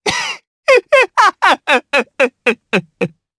Kibera-Vox_Happy3_jp.wav